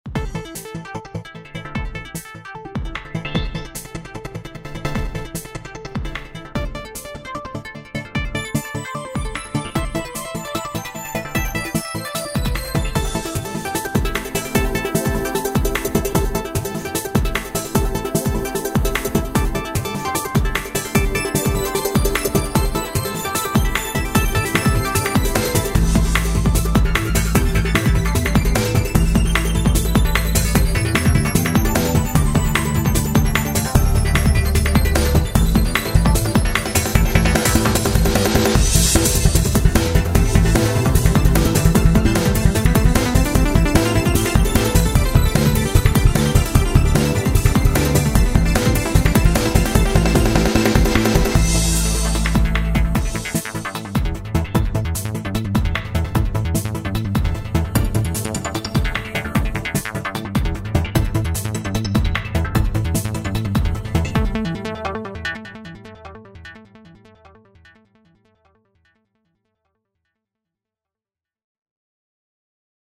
Multisample-based, fully-featured synthesizer engine